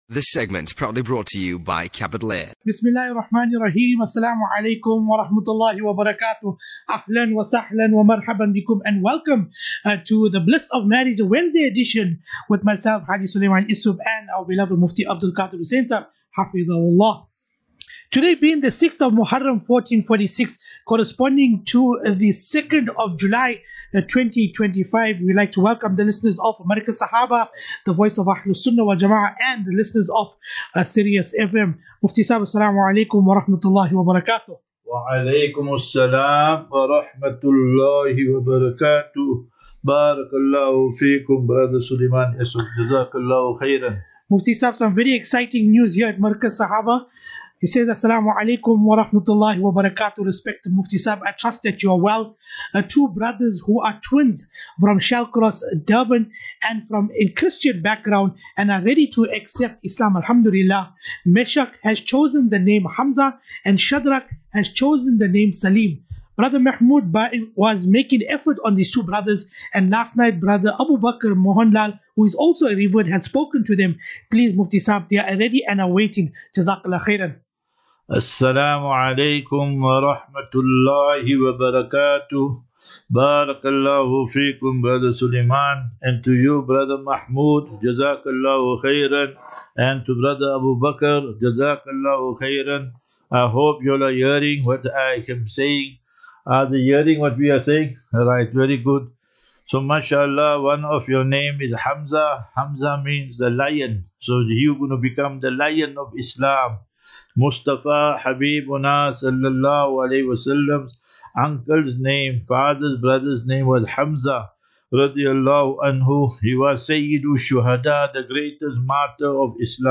2 Twin brothers accepted is Islam live on air at Markaz Sahaba online Radio